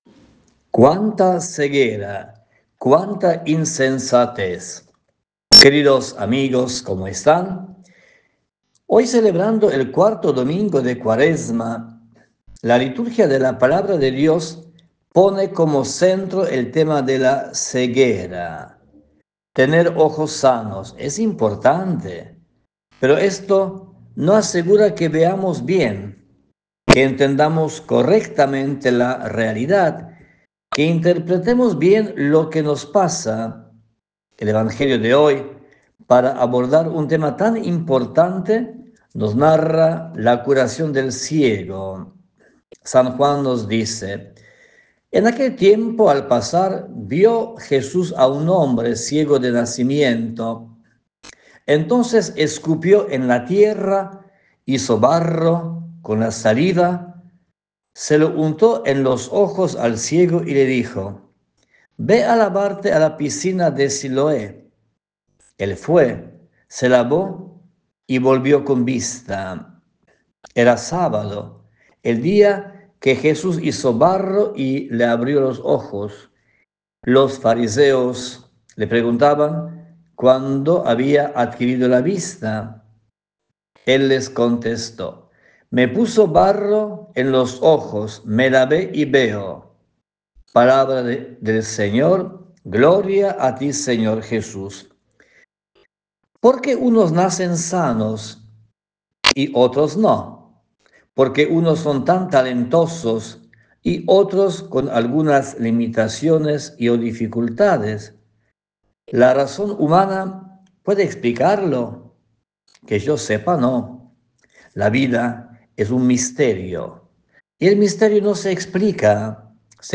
La reflexión dominical